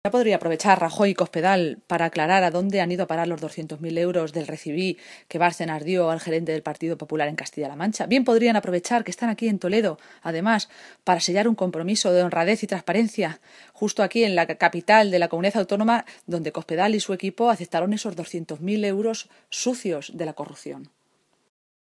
Maestre se pronunciaba de esta manera esta mañana, en una comparecencia ante los medios de comunicación en la capital de Castilla-La Mancha, donde hoy coincidía con la reunión del comité nacional de dirección del PP.
Cortes de audio de la rueda de prensa